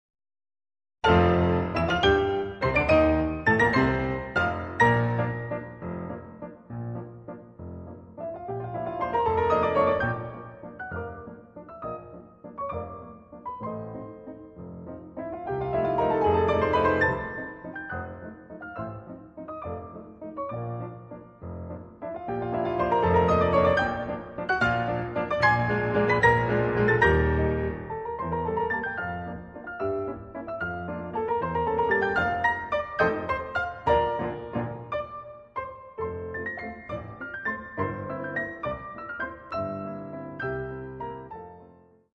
Walzer, Polkas und Märsche
Transkriptionen für Klavier zu vier Händen